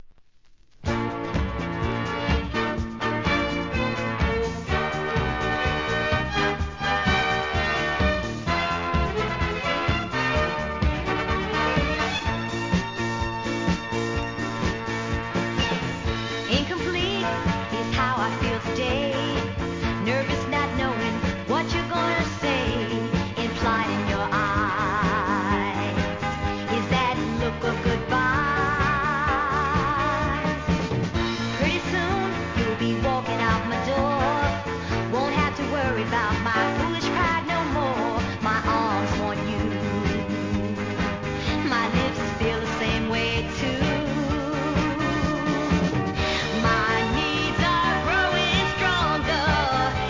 店舗 数量 カートに入れる お気に入りに追加 1975年のキュートなDISCO CLASSIC!!